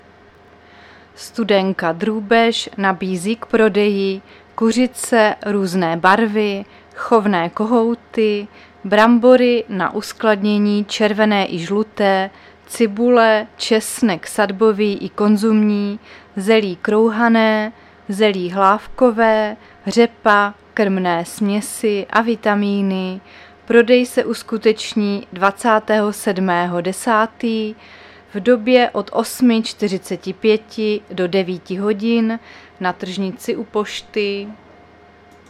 Záznam hlášení místního rozhlasu 24.10.2023
Zařazení: Rozhlas